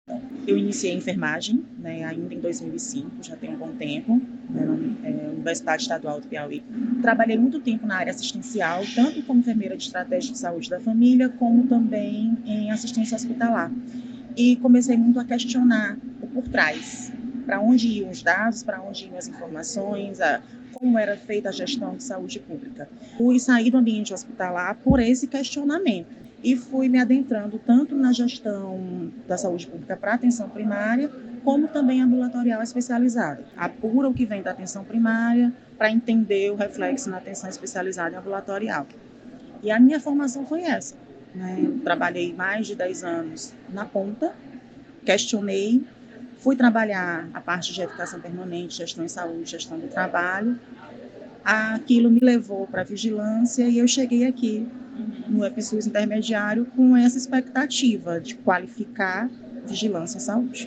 Ouça o depoimento da aluna: